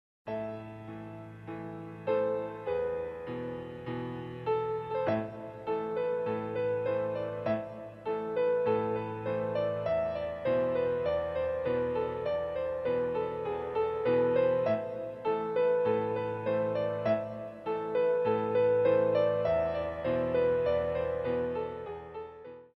46 Piano Selections.